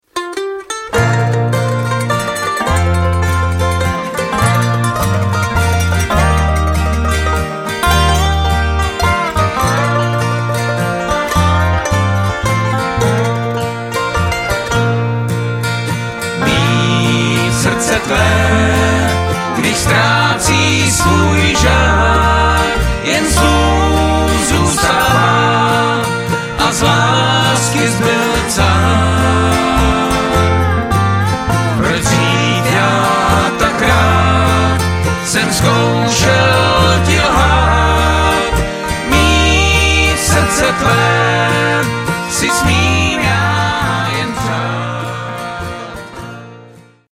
lead
tenor
baritone